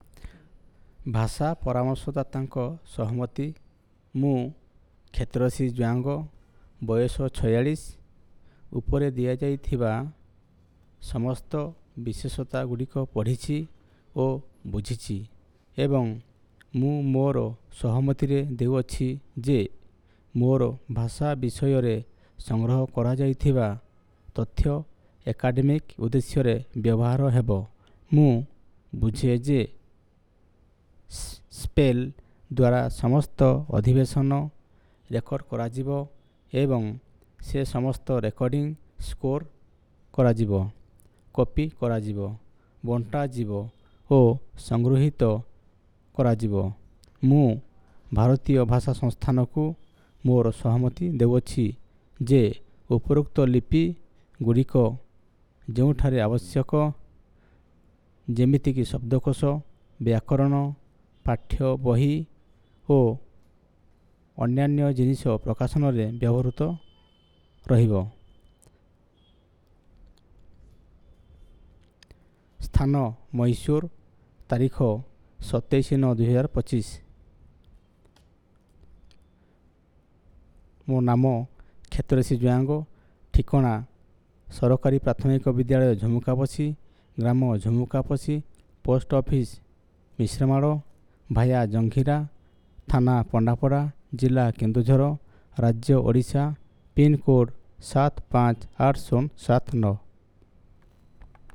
Elicitation of socio-linguistic profile of informant
NotesThis is an elicitation of profile of informant with his consent for documenting the language by SPPEL